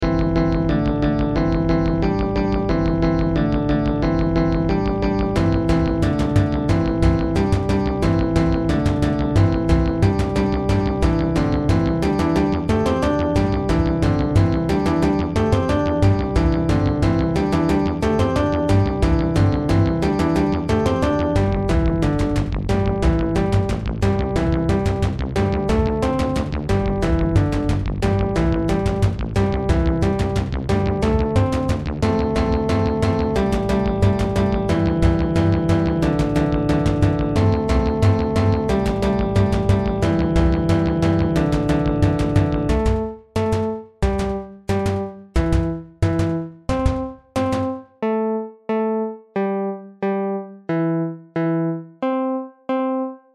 drumsnbells_0.mp3